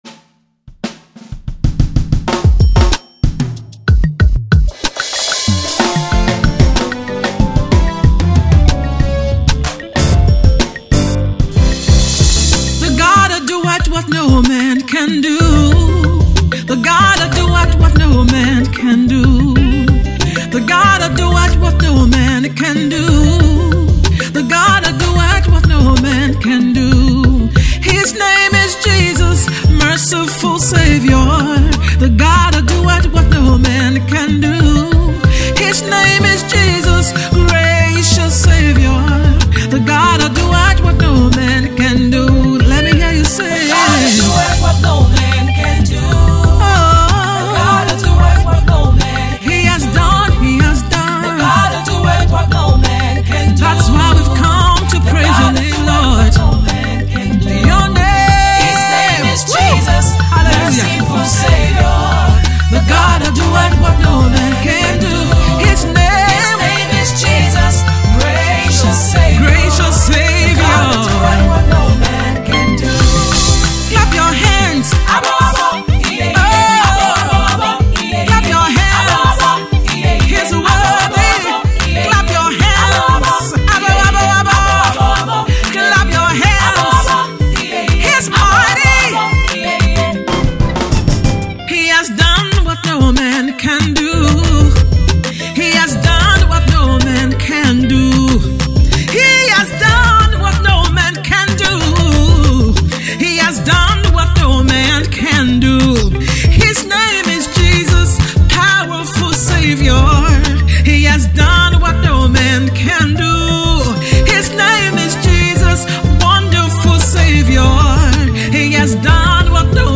praise anthem